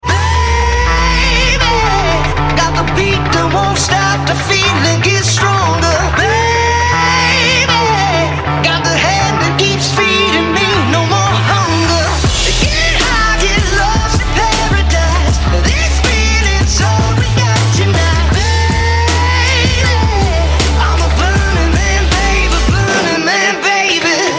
• Качество: 128, Stereo
громкие